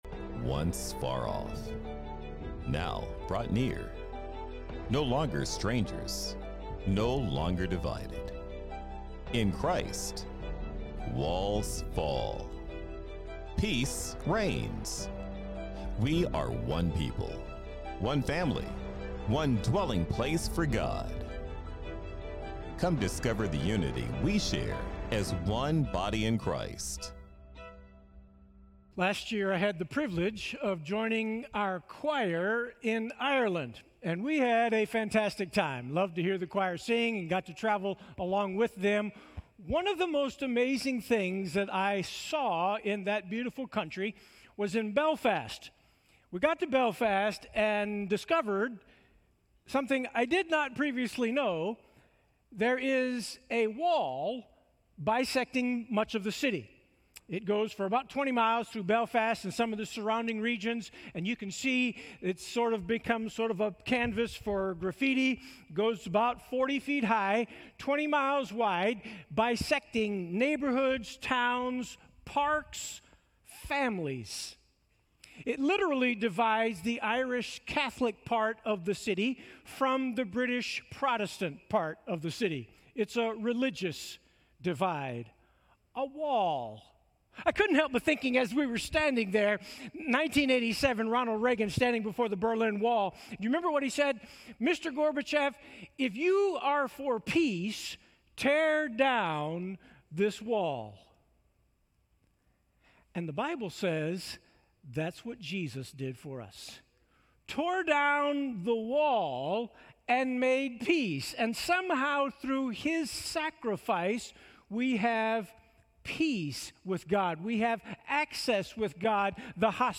Sermons - Sunnyvale FBC